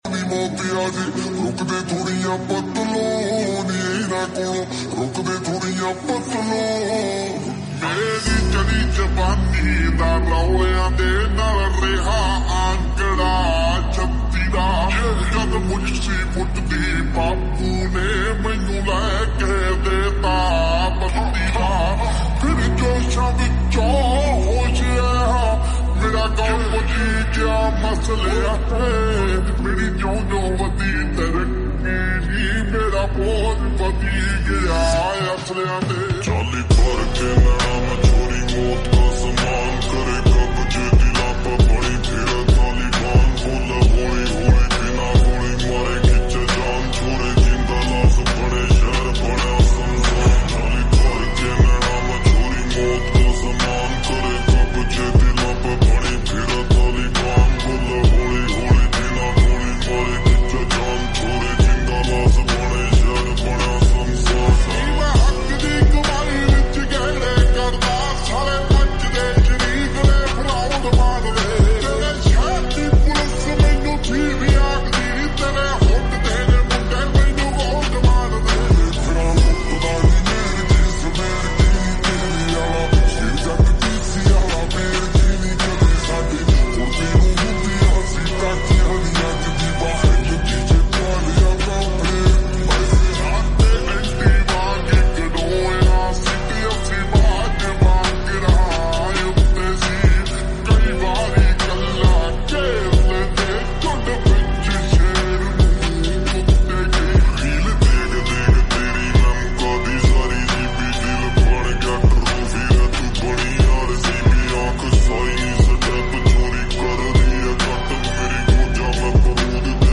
𝐌𝐄𝐒𝐇𝐔𝐏 𝐒𝐎𝐍𝐆 🥵 (𝐒𝐎𝐋𝐕𝐄𝐃 𝐑𝐄𝐕𝐄𝐑𝐁